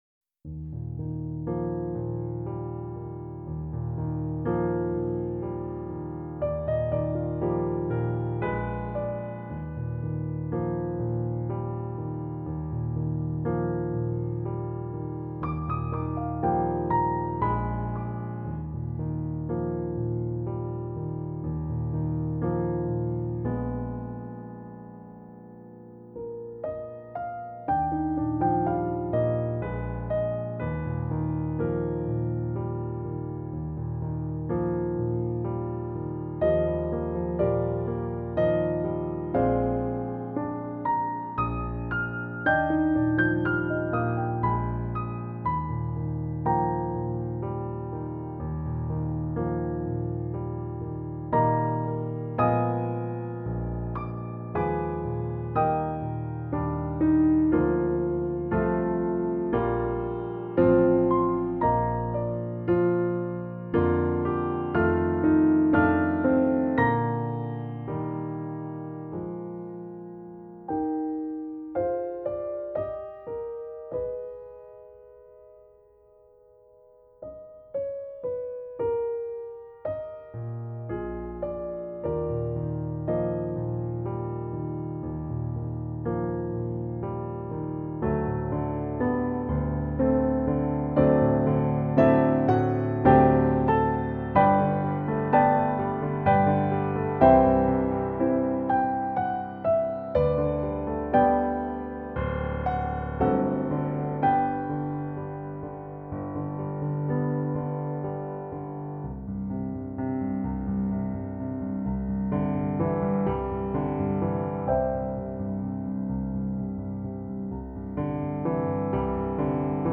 One piano, four hands.